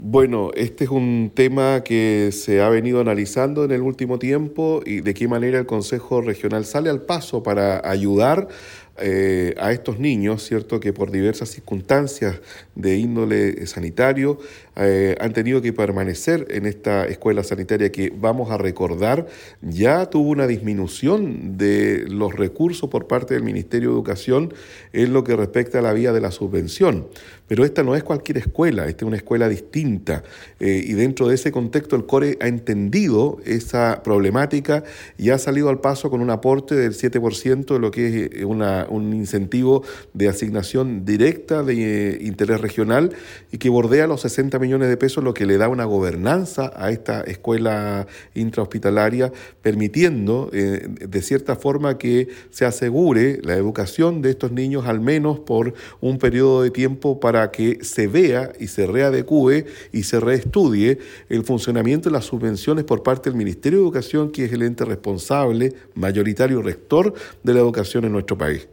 En este contexto, el Consejero Regional, Patricio Fuentes, expresó que, “los recursos aprobados por nuestro Consejo Regional, permite una gobernanza independiente para la Escuela Hospitalaria lo que asegura la educación de estos estudiantes por lo menos durante un periodo de tiempo, durante el cual pueda ser regulada la situación del funcionamiento de la subvención por parte del Ministerio de Educación que es el ente responsable de la administración y financiamiento del recinto”.